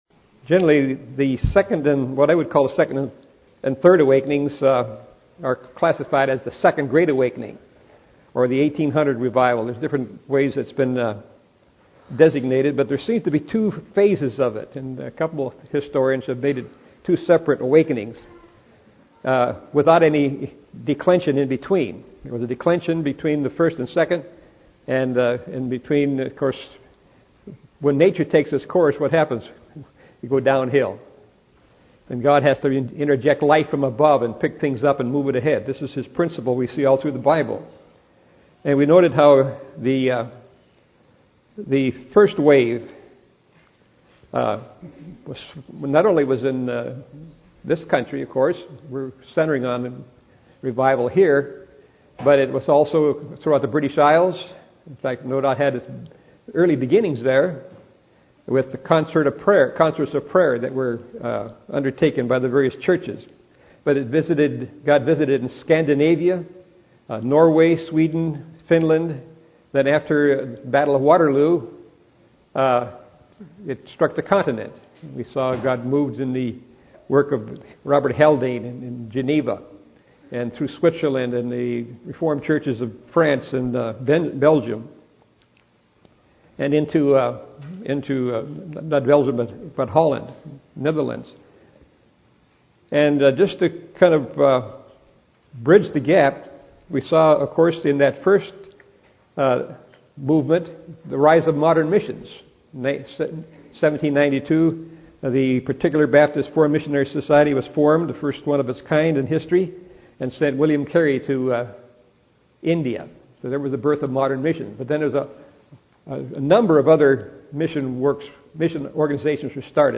Series: 2010 July Conference Session: Morning Session